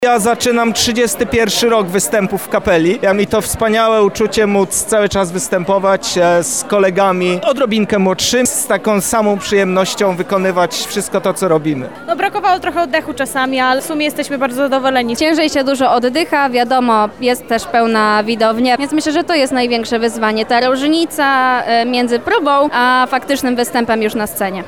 O wydarzeniu opowiedzieli również sami wykonawcy: